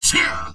文件 文件历史 文件用途 全域文件用途 Enjo_atk_03_1.ogg （Ogg Vorbis声音文件，长度0.5秒，156 kbps，文件大小：10 KB） 源地址:地下城与勇士游戏语音 文件历史 点击某个日期/时间查看对应时刻的文件。